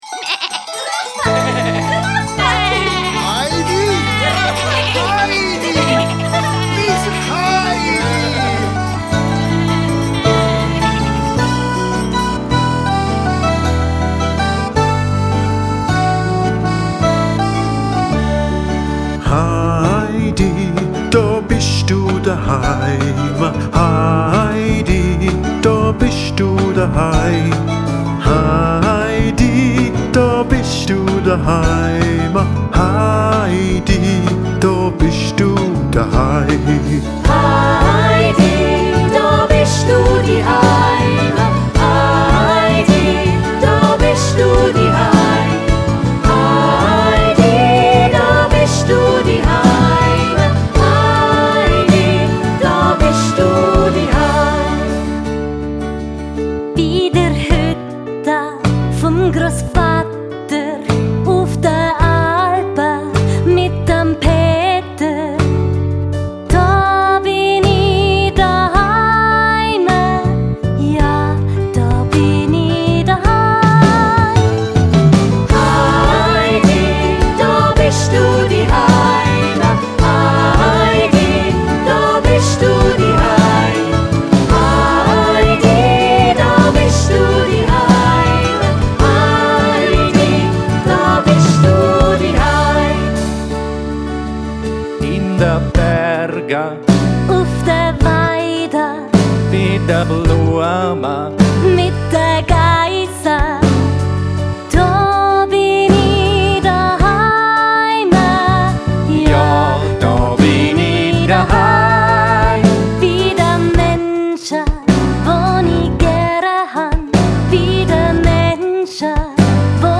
Familienmusical mit aktuellen Lebensthemen